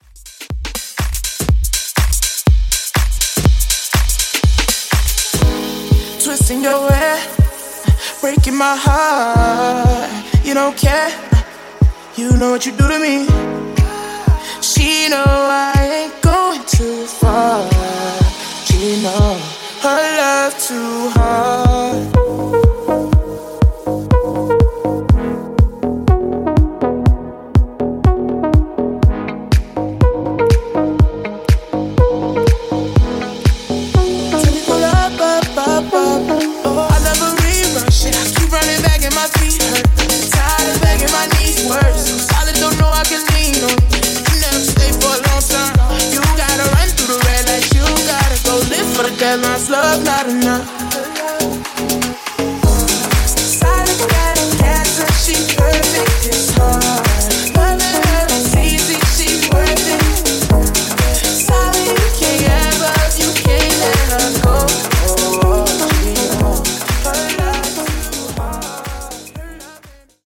Club Extended Mix)Date Added